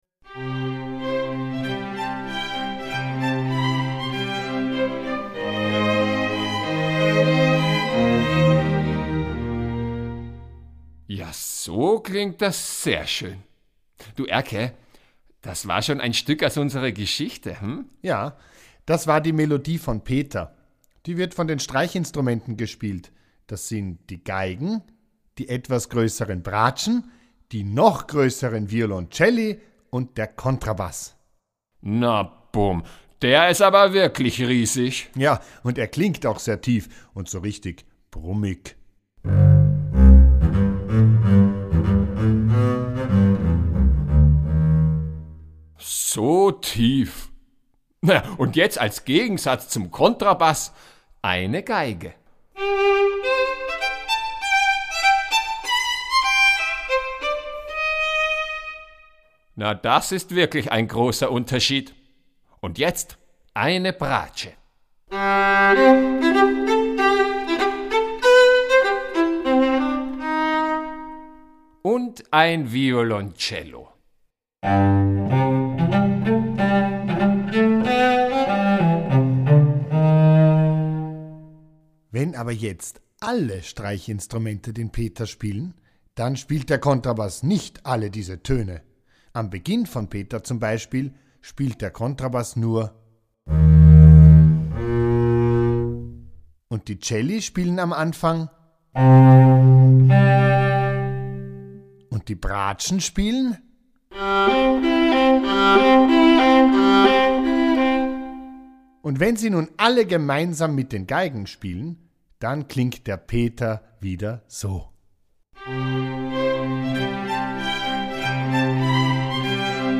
Ravensburger Peter und der Wolf ✔ tiptoi® Hörbuch ab 5 Jahren ✔ Jetzt online herunterladen!